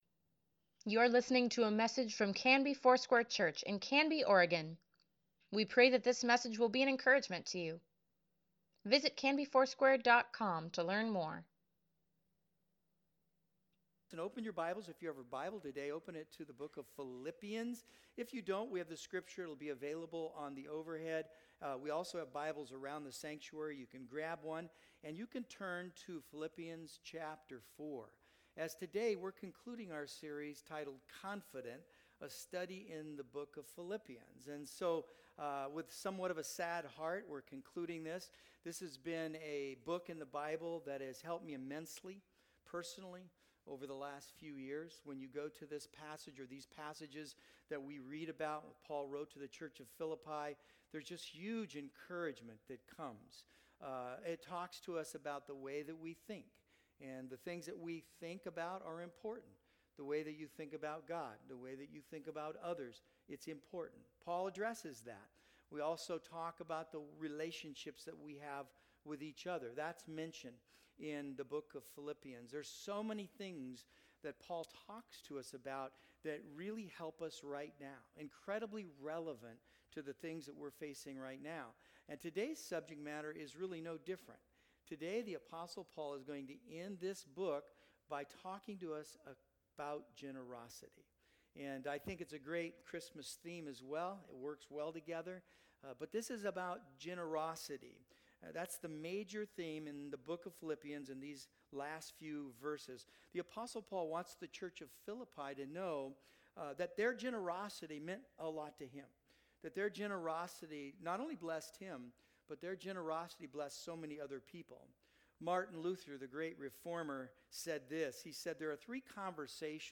Weekly Email Water Baptism Prayer Events Sermons Give Care for Carus CONFIDENT: Philippians 4:14-23 December 12, 2021